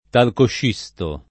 talcoscisto [ talkošš &S to ]